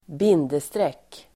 Uttal: [²b'in:destrek:]